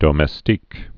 (dōmĕs-tēk)